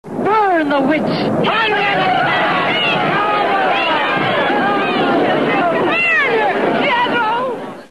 Soundfile1 - The crowd scream out the judgment from the old B & W  flick 'Horror Hotel'